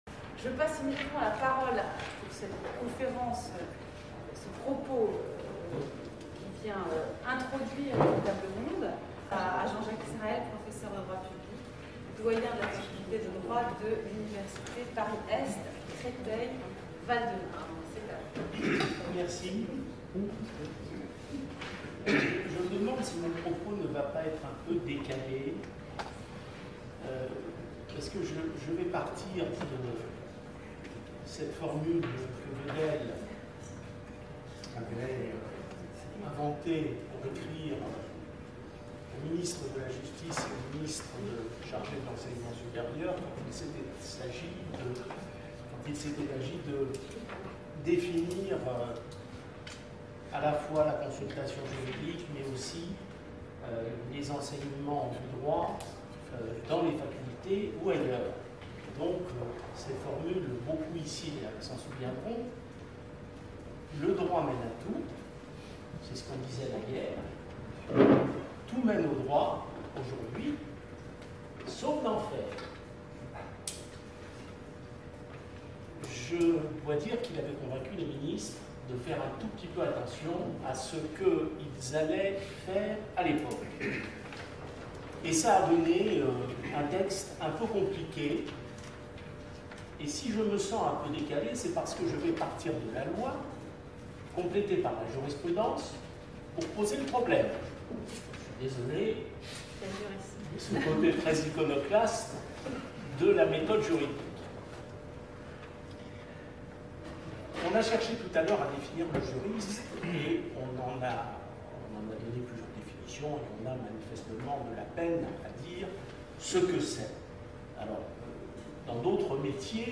Colloque : Enseigner le droit au XXIème siècle. Table ronde : Où former les juristes ?